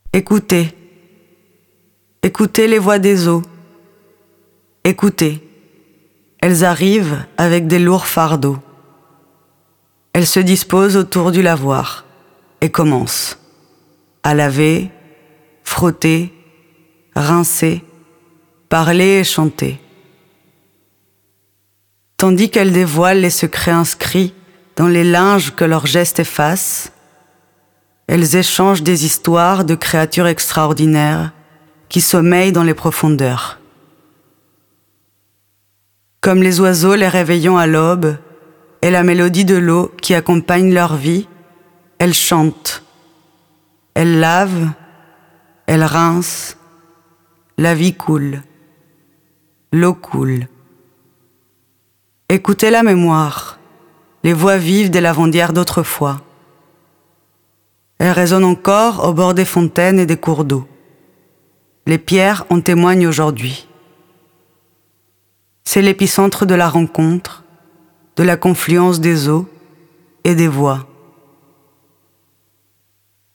Elle a également enregistré certaines voix du lavoir, que l’on peut écouter via des QR Codes auprès de l’œuvre ou ci-dessous :